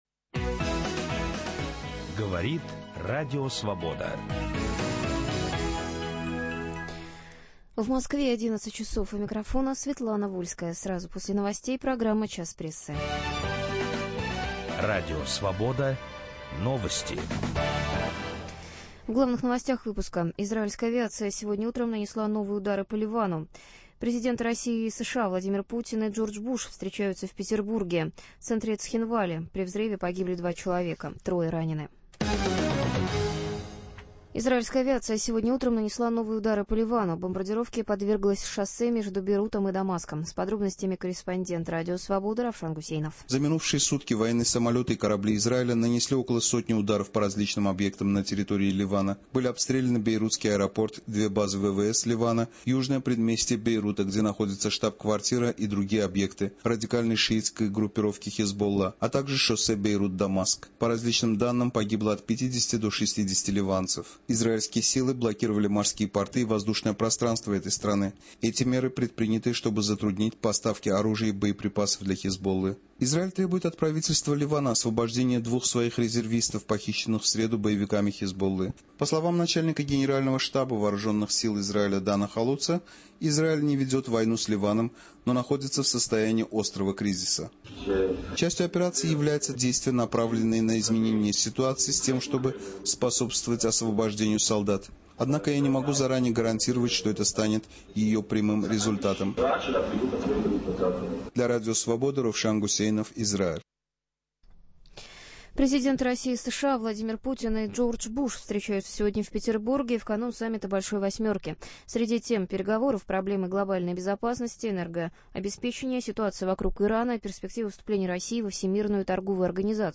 Саммит "Большой восьмерки" сквозь призму российской прессы. Беседуют